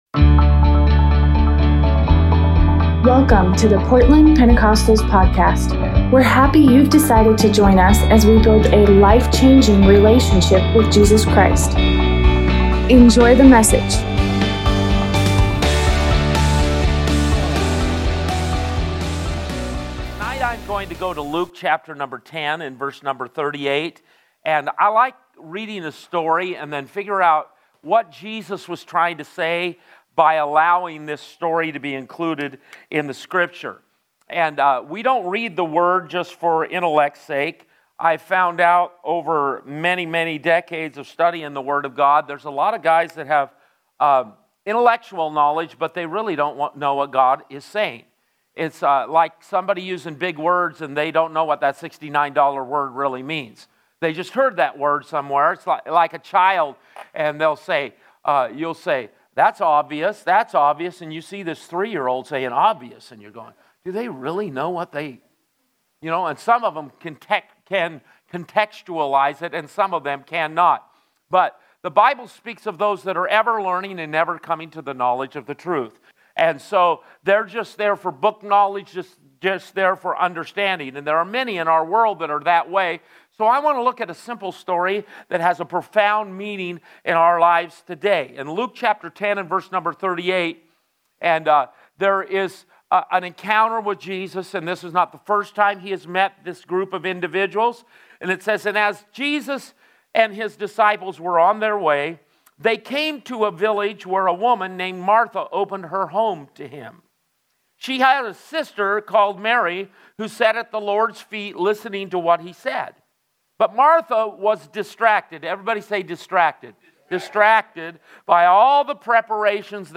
Tuesday night Bible study